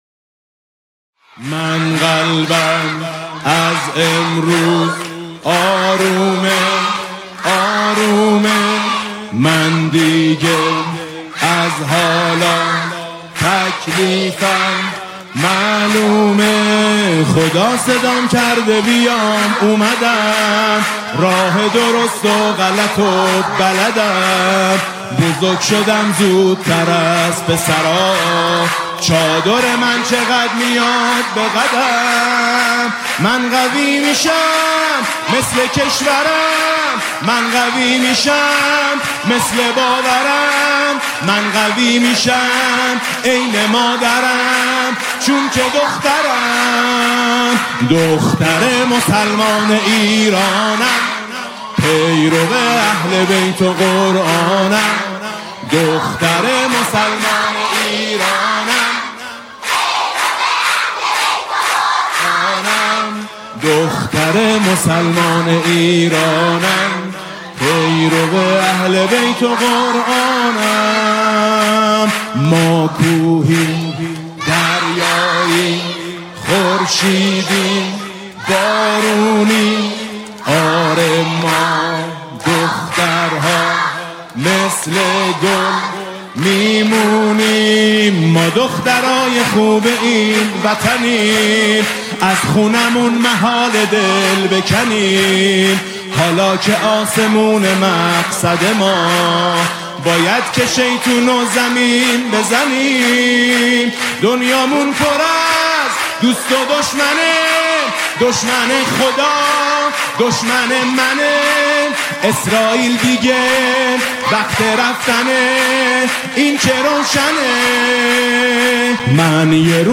با همخوابی دختر بچه